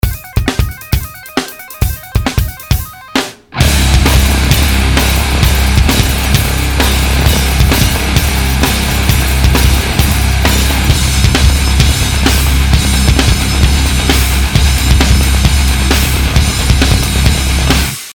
Громкий проигрыш без слов из начала композиции Ура!